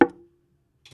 knock.wav